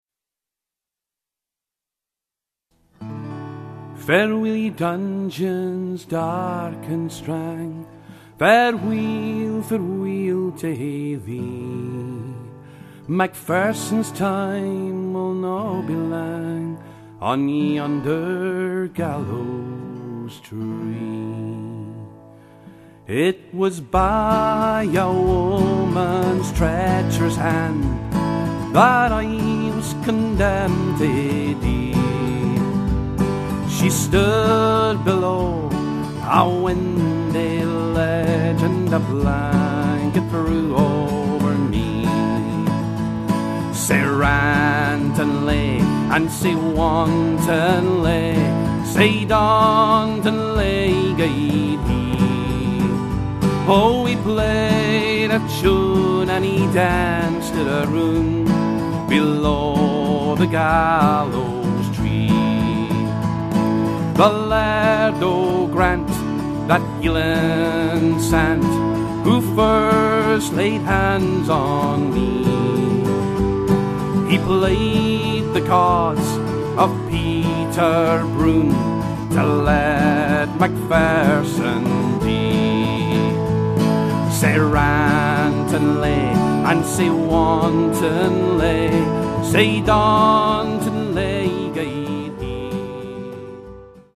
He is becoming the vocalist of choice at Scottish and Irish events in Northern California and beyond, he now resides in Mountain View, CA. Blessed with a strong smooth tenor voice he sings the only way he knows how, with power and passion and depth of feeling.